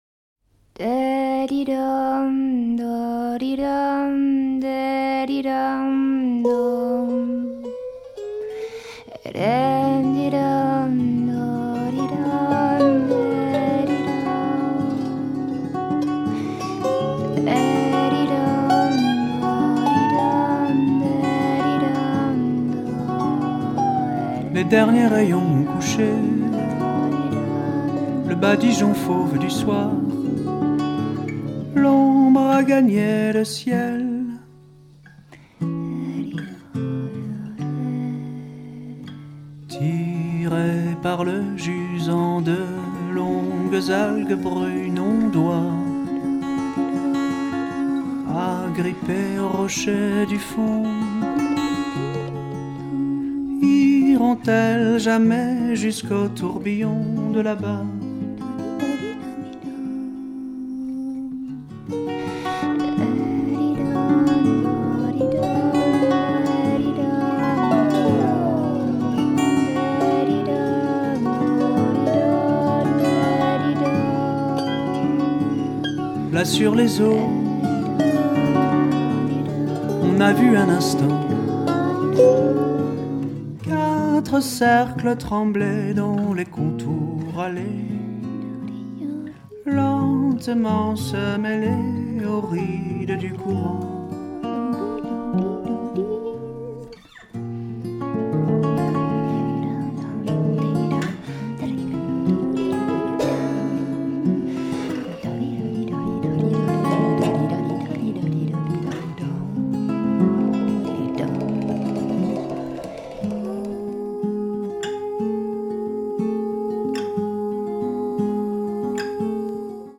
最高！ワールドミュージックのエッセンスを色濃く反映させた、美しくも風変りなフォーク・ミュージック！